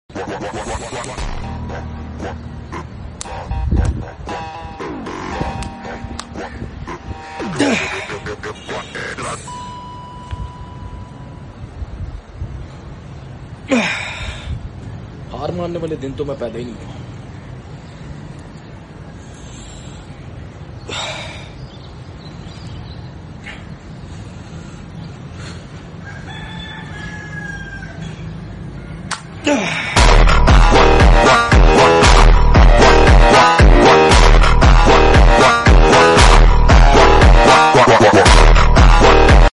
Industrial cable tie 🔥 sound effects free download